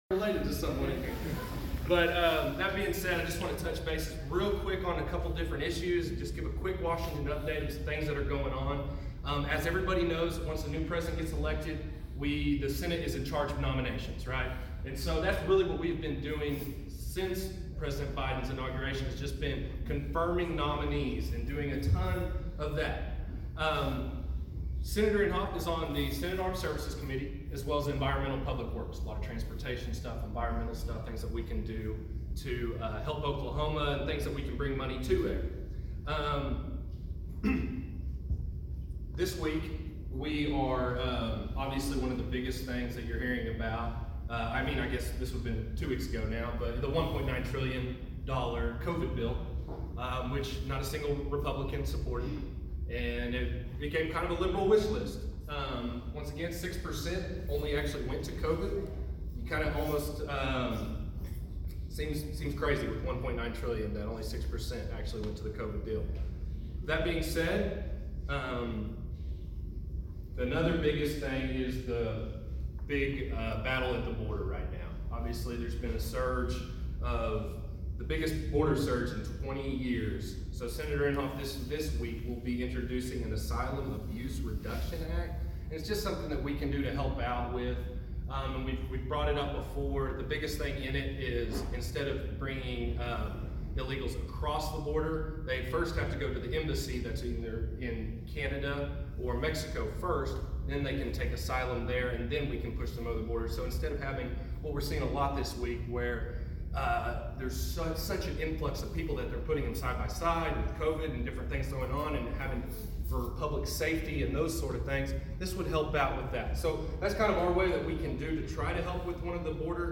The installation and the presentations took place at the Green Country Republican Women's Club Luncheon on Thursday, March 25, at Hillcrest Country Club in Bartlesville.